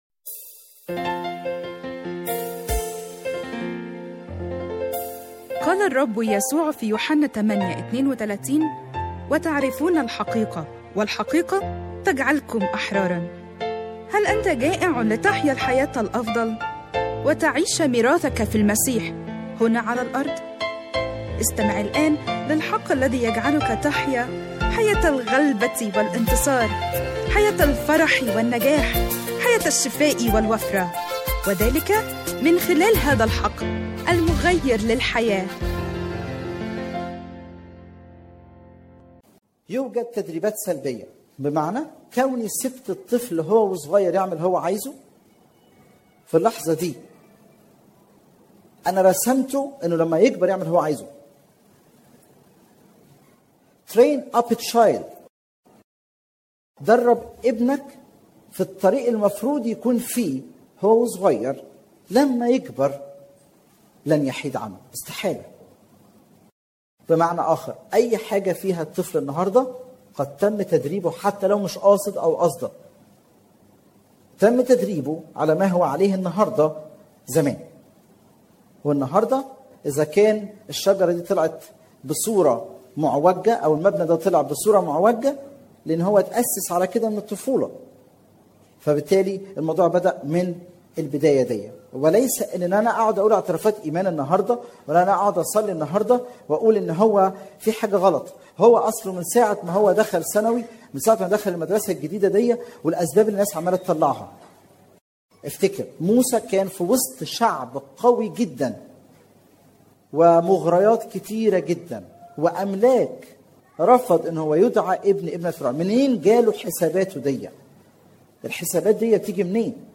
*تنويه: هذه السلسلة متاحة مسموعة في اكثر من عظة اما النص المكتوب فهو للسلسلة كاملة (العظة مكتوبة تجدونها في الجزء الأول) لسماع باقي أجزاء السلسلة اضغط هنا